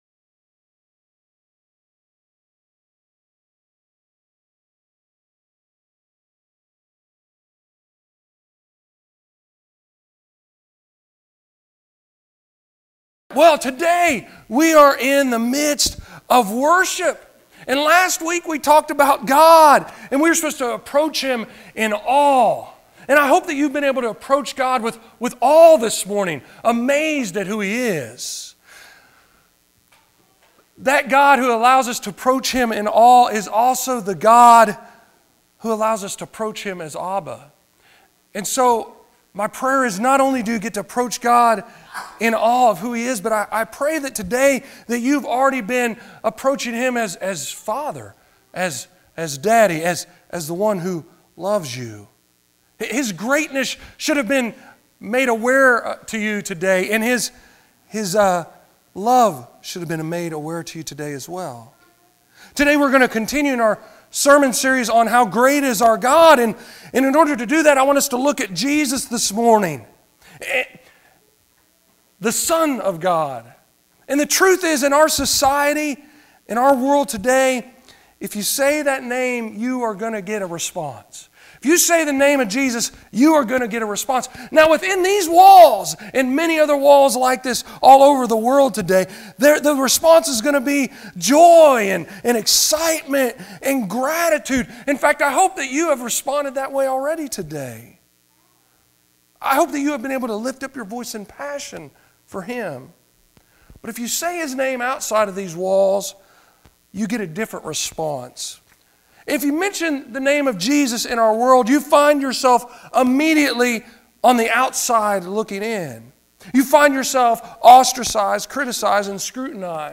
23:22 Sermons in this series Being Filled With The Holy Spirit!